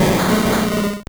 Cri de Smogogo dans Pokémon Or et Argent.